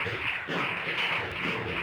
drums04.wav